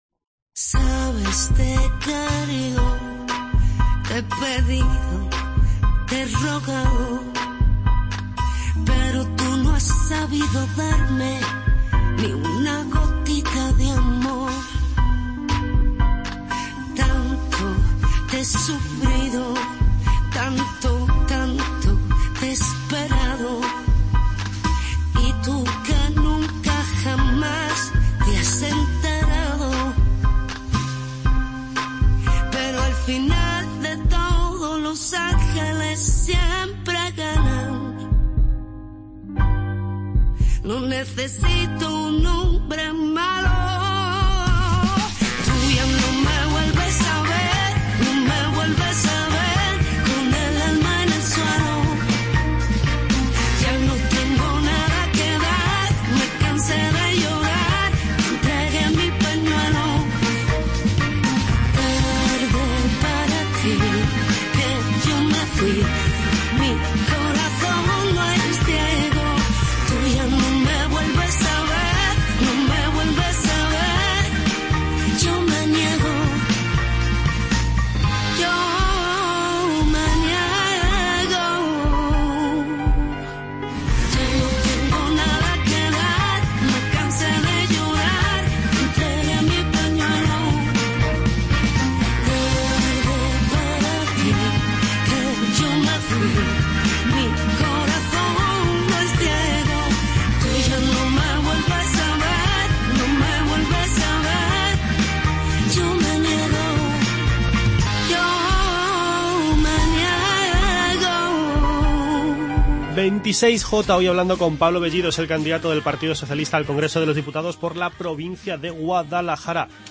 Entrevista con Pablo Bellido, cabeza de lista al Congreso de los Diputados del Partido Socialista por la provincia de Guadalajara.